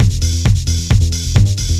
TECHNO125BPM 10.wav